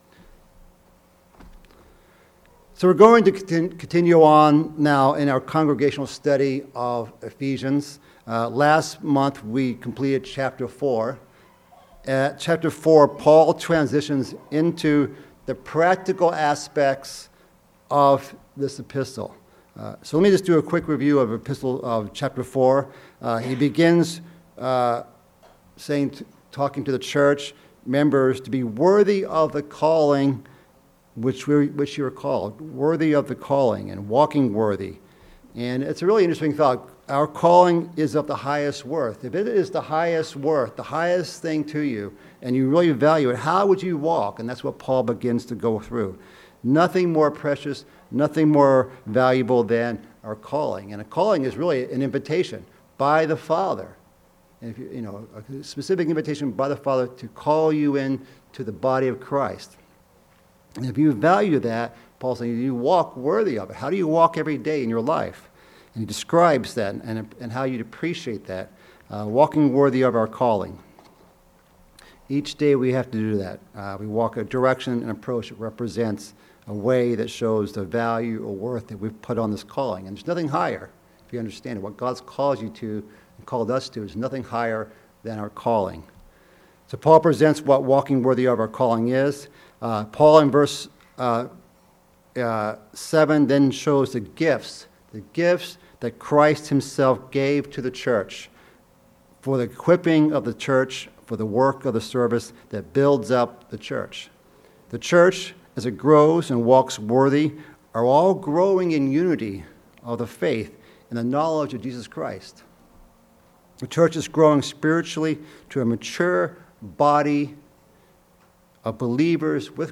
This study, given in Chicago and virtually given in NW Indiana and Beloit Wisconsin, covers verses 1-14 of Ephesians 5